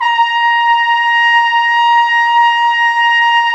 Index of /90_sSampleCDs/Roland L-CD702/VOL-2/BRS_Flugel Sect/BRS_Flugel Sect